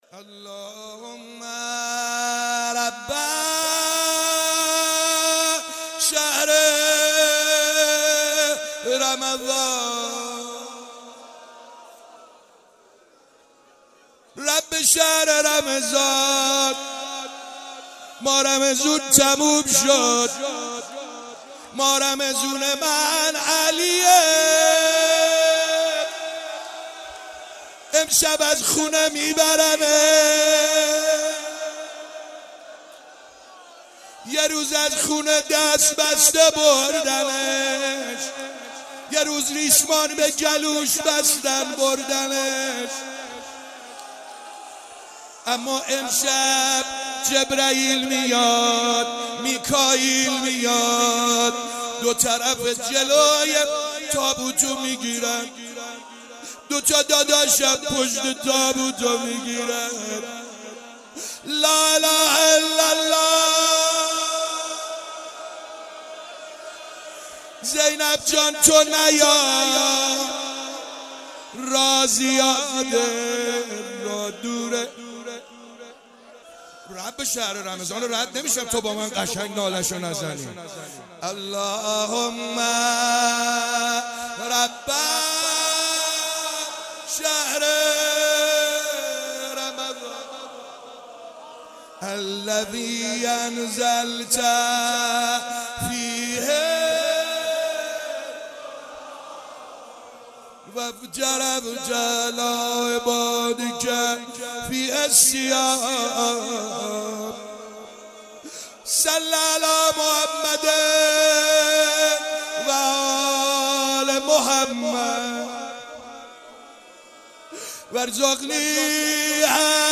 شب 21 ماه مبارک رمضان_روضه امیرالمومنین علیه السلام